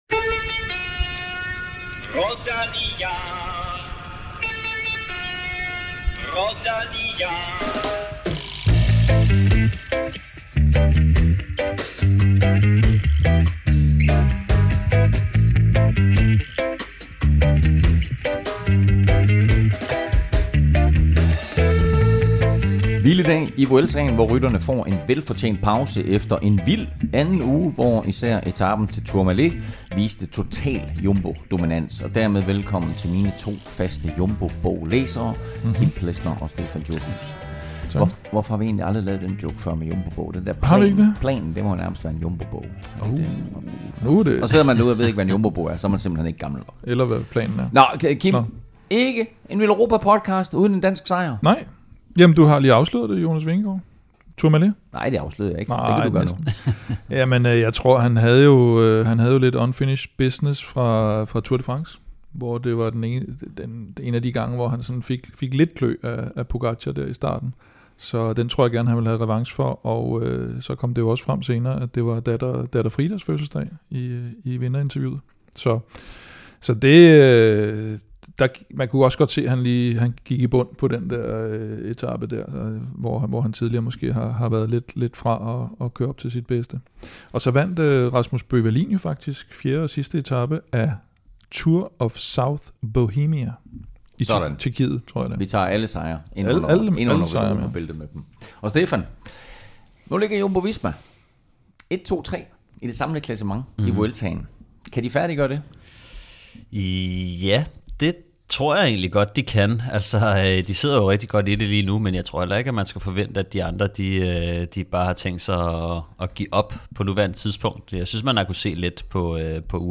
Interview med Kron, Remcos op- og nedtur
Du kan også høre fra Vuelta-etapevinder Andreas Kron og så har vi selvfølgelig også nyt fra de andre store løb rundt omkring i verden.